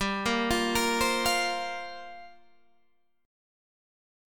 Gm11 chord